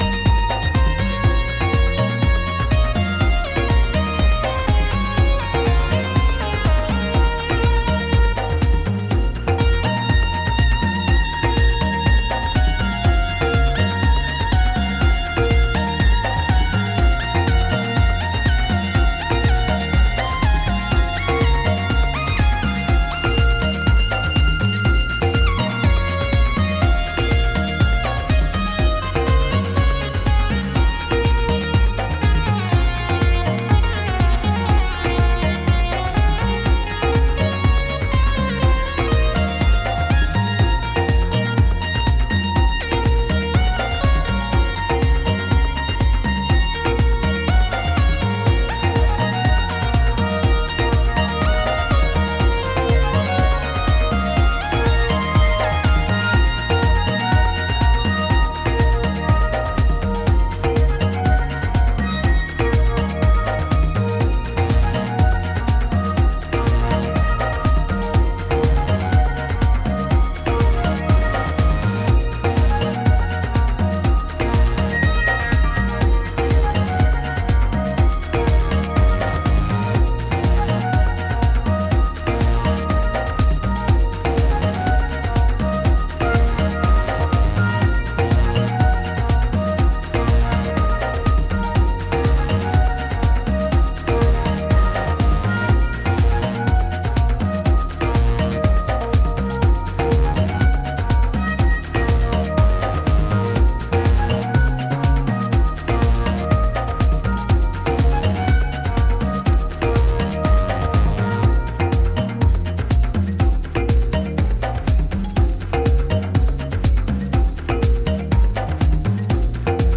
guaranteed Afro Latin anthem